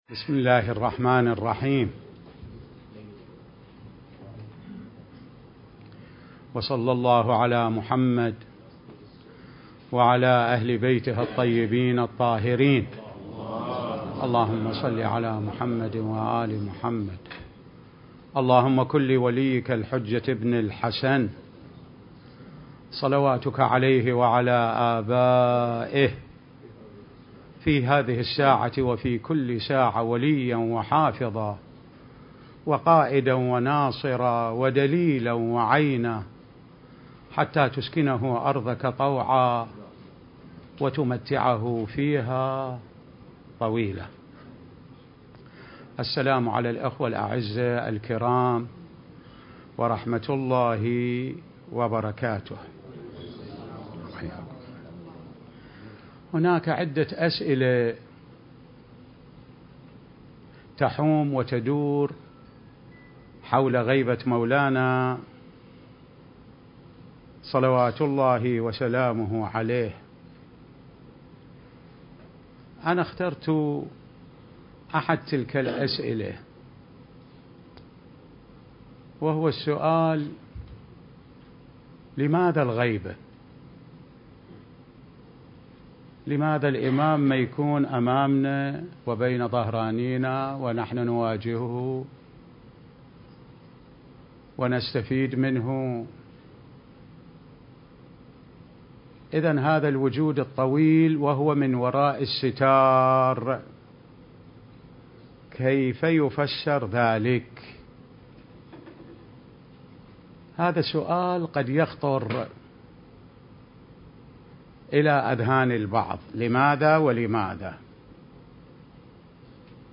كلمة
المكان: العتبة العلوية المقدسة